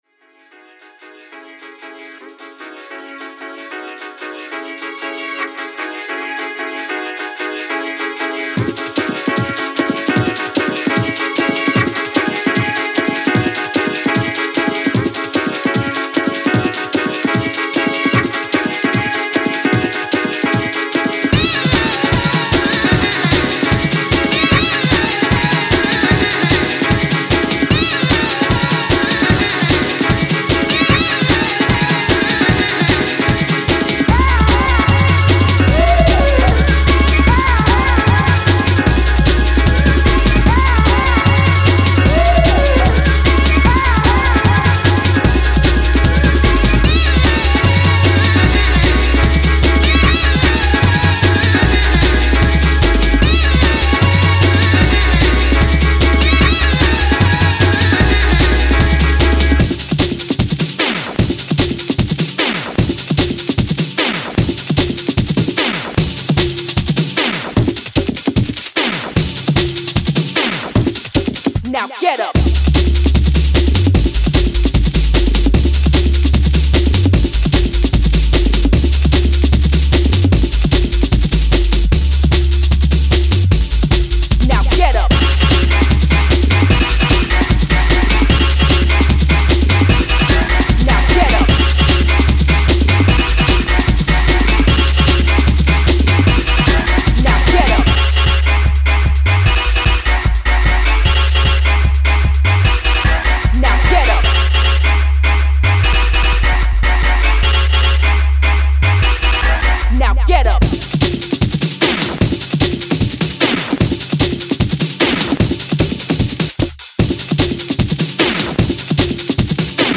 high-energy, sample-laden tracks